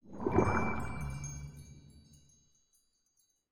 Minecraft Version Minecraft Version latest Latest Release | Latest Snapshot latest / assets / minecraft / sounds / entity / glow_squid / ambient3.ogg Compare With Compare With Latest Release | Latest Snapshot